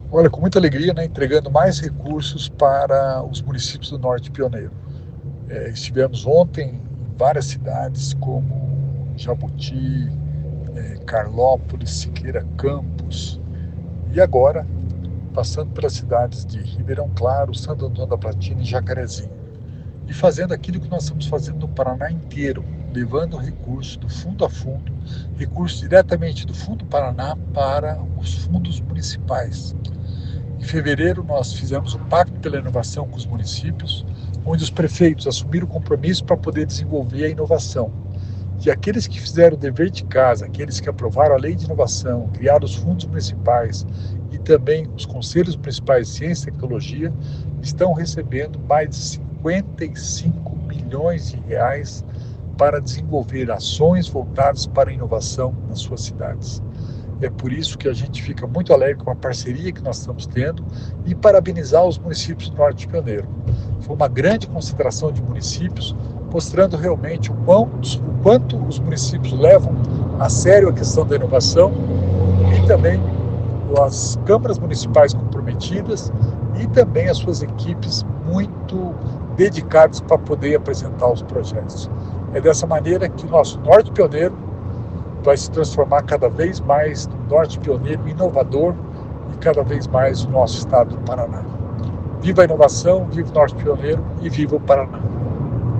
Sonora do secretário da Inovação e Inteligência Artificial, Alex Canziani, sobre os repasses do Pacto Pela Inovação a municípios do Norte Pioneiro